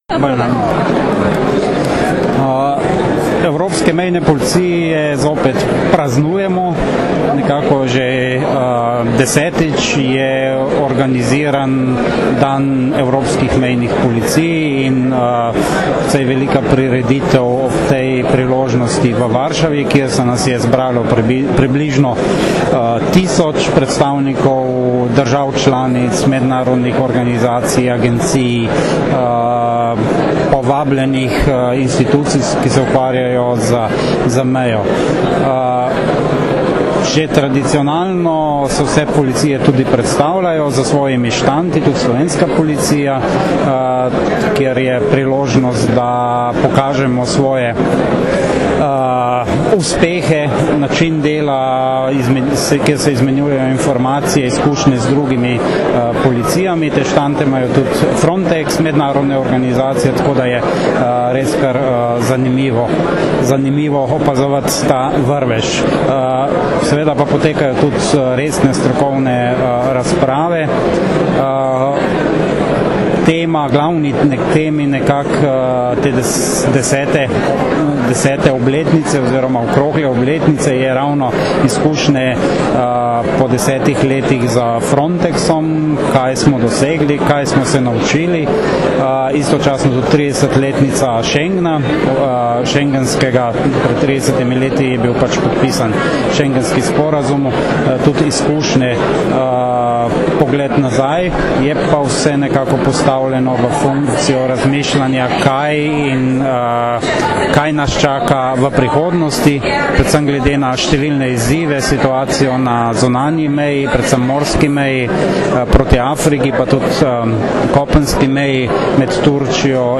Zvočni posnetek izjave Marka Gašperlina, (mp3)
pomočnika direktorja Uprave uniformirane policije in podpredsednika Upravnega odbora agencije Frontex